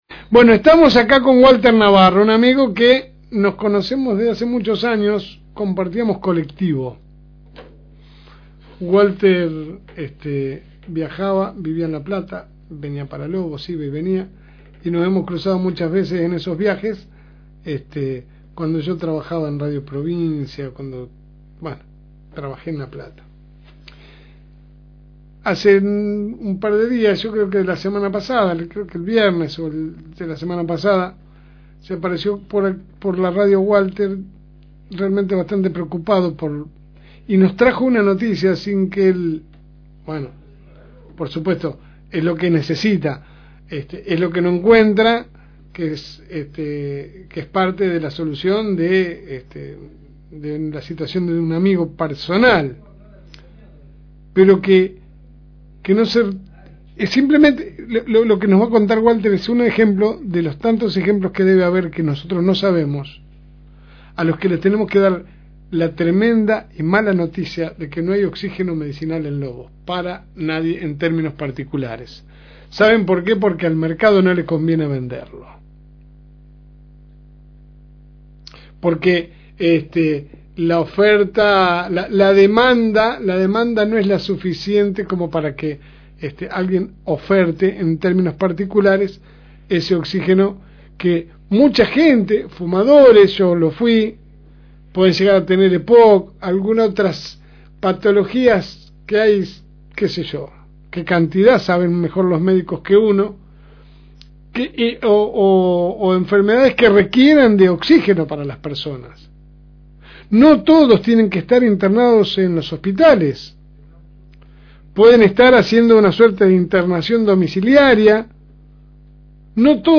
AUDIO – Escasez de oxígeno medicinal en Lobos: pacientes con problemas respiratorios en alerta – FM Reencuentro